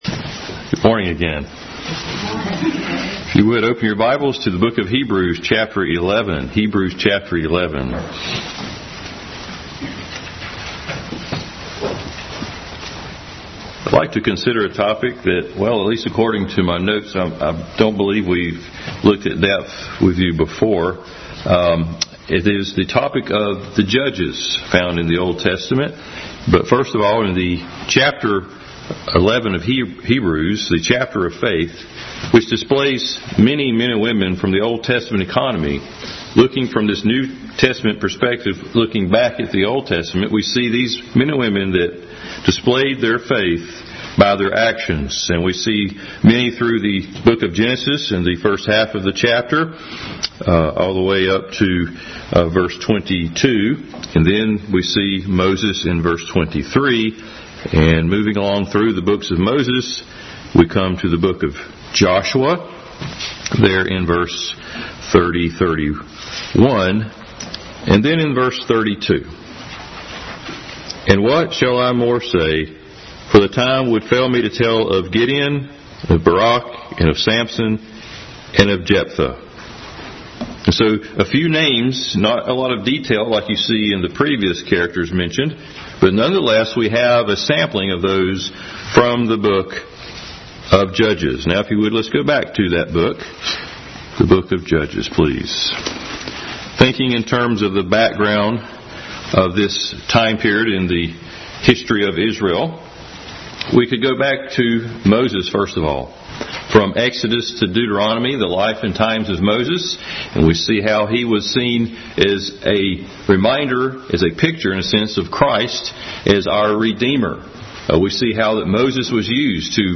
Bible Text: Hebrews 11:32, Judges 1:1-20, 2:6-23, 3:7-30, 17:6, 18:1, random verses from Chapters 4,5,6,7,8,9,11,12, and 13-16, 2 Corinthians 10:1-13 | Family Bible Hour Message – an introduction to the Judges.